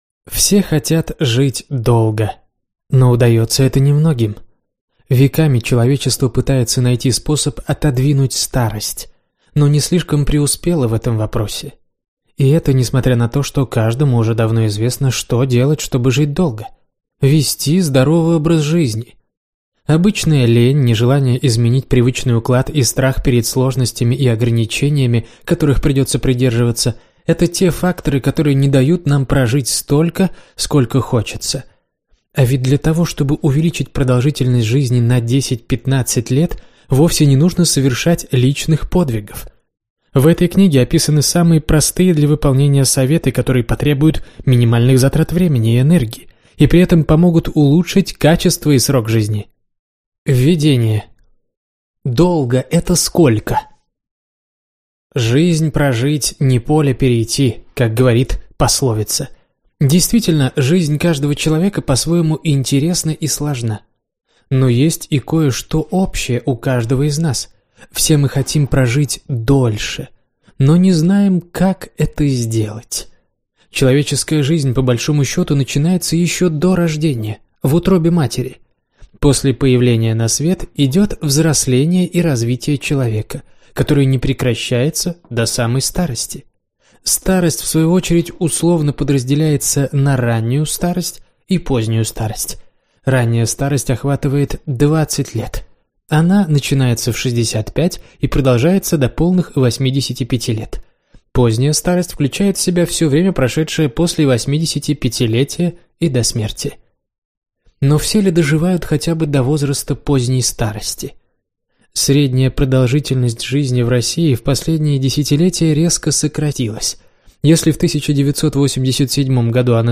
Аудиокнига Как жить до ста: правила долголетия | Библиотека аудиокниг